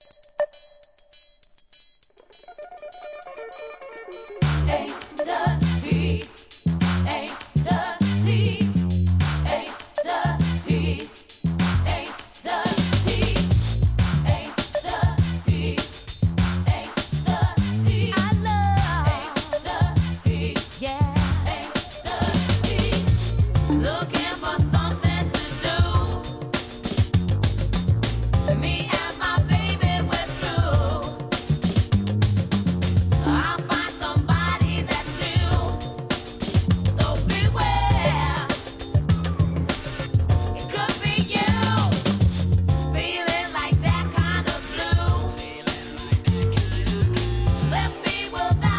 ACID JAZZ!